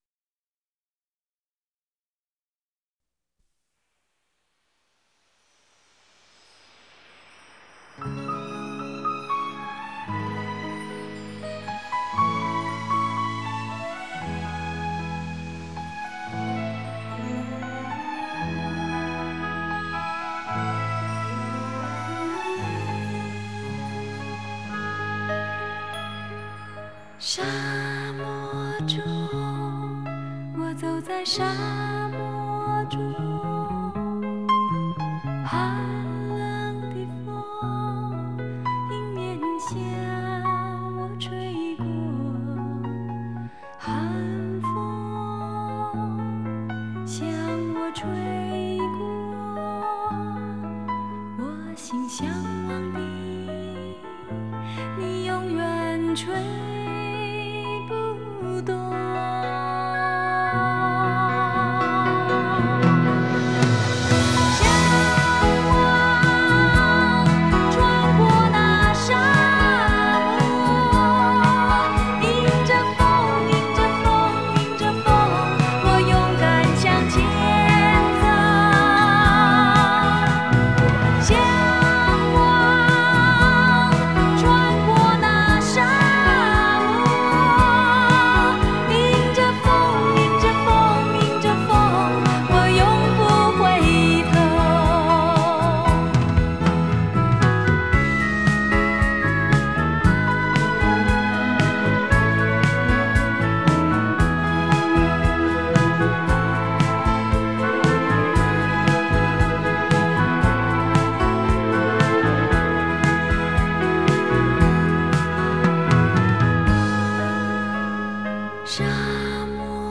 Жанр: Chinese pop / Pop